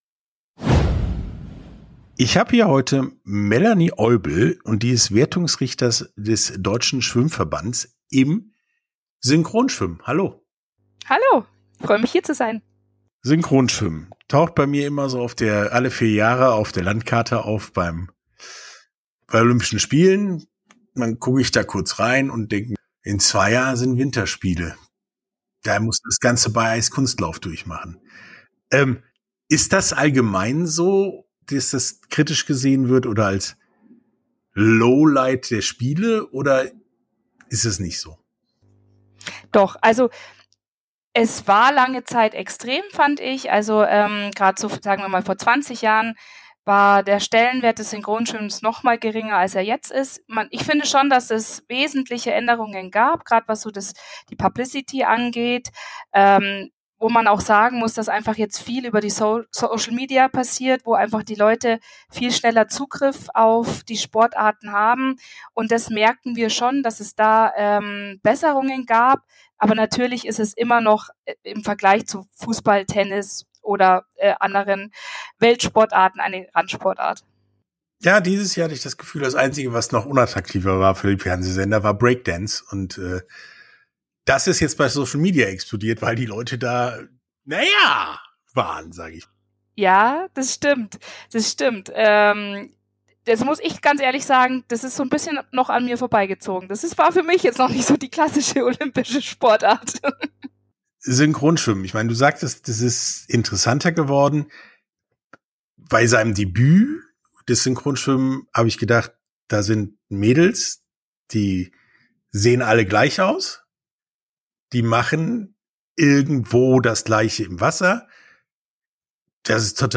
Sportstunde - Interview komplett Synchronschwimmen ~ Sportstunde - Interviews in voller Länge Podcast